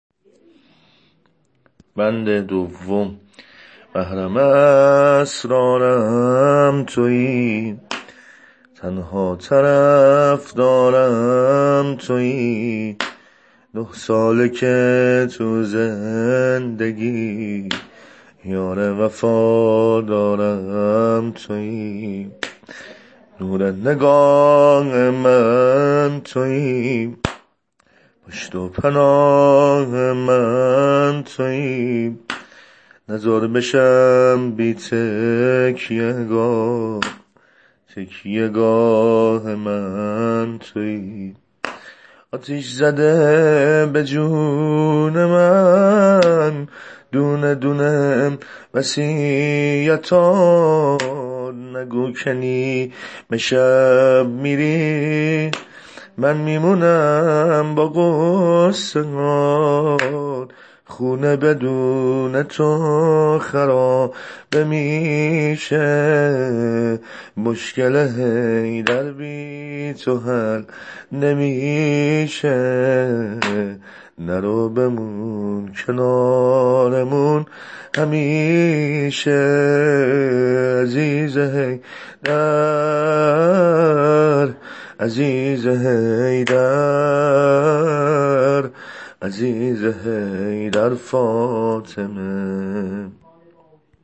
سبک واحد سنگین شهادت حضرت زهرا سلام الله علیها زبانحال حضرت علی علیه السلام -(نگار من کمون شدی/بهار من خزون شدی)